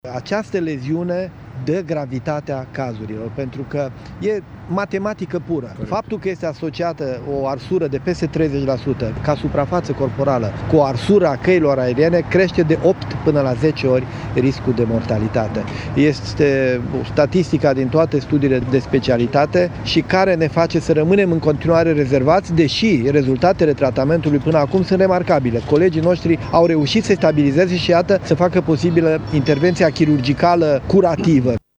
Secretarul de stat în Ministerul Sănătăţii, Dorel Săndesc, specialist în reanimare, a explicat din ce cauză numărul răniţilor este atât de mare: